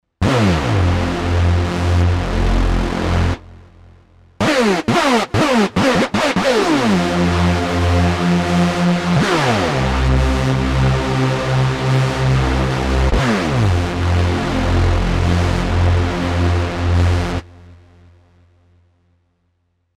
I give you half decent hoover :slight_smile:
this seems better , i am using unison where i don’t want to, has balls though , god i wish my alpha was not away
Instead of unison, I’m using 2 saws, 1 is up an octave, both have -1 oct subs, both have a little PWM. That’s 4 OSCs. Add chorus for more.